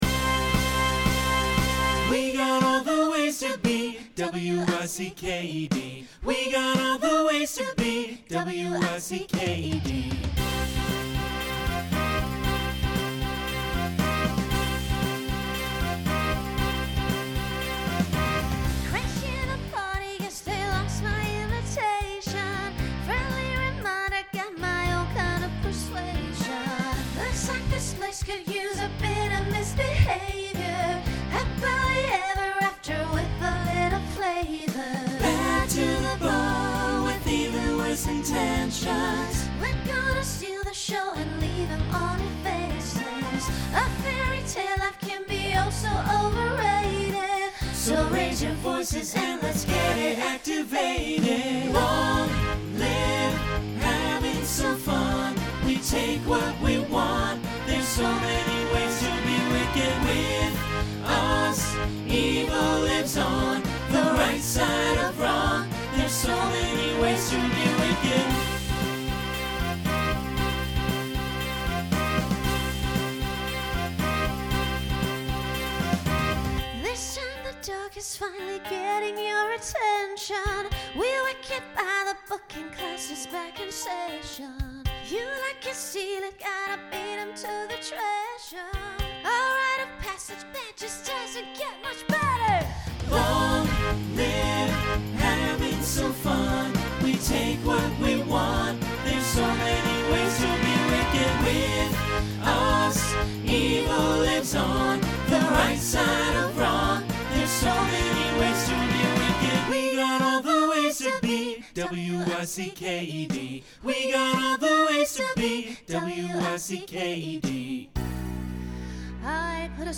Broadway/Film , Pop/Dance
Voicing SATB